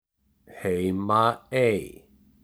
Summary Description Heimaey pronunciation.ogg English: Pronunciation of the name of this Icelandic place.
Heimaey_pronunciation.ogg